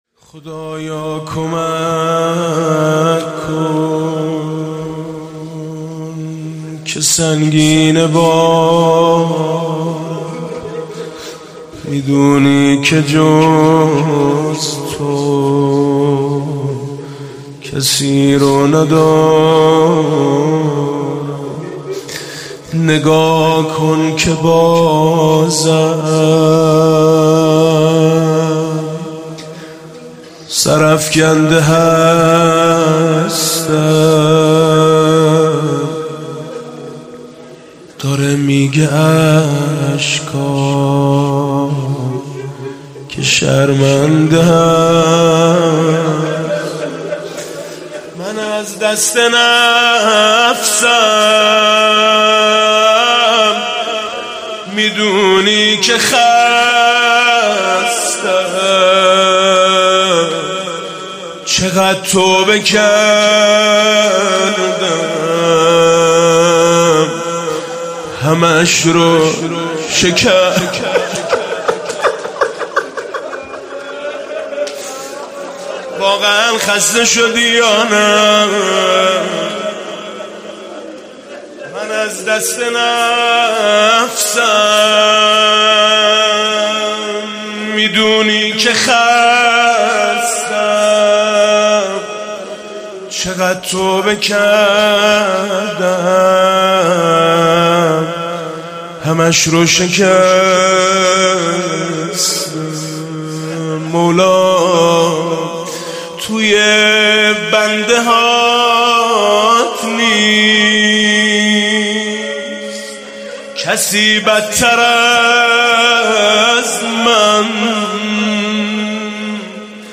مداحی جدید حاج ميثم مطيعی شب دوم رمضان هیات میثاق با شهدا سه شنبه 17 اردیبهشت 1398